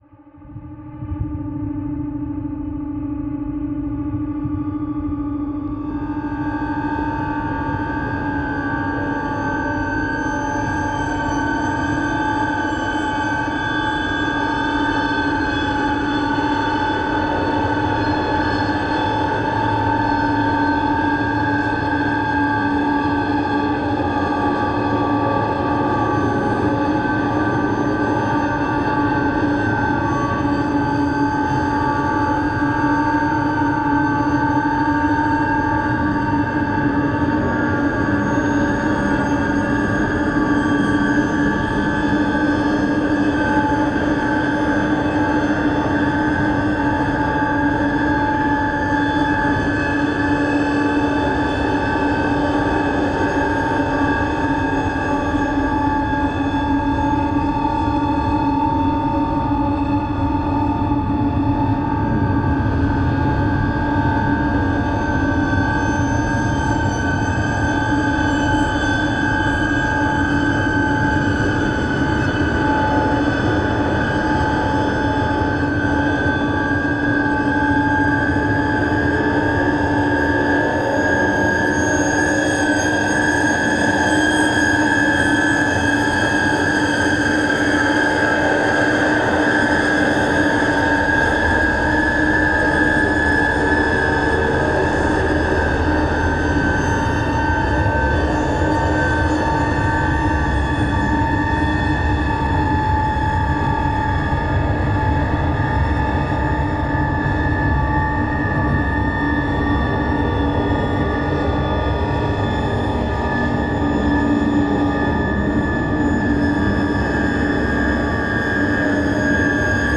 soundscapes tension dark ambient horror